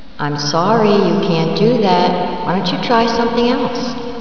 COMPUTER VOICE FILES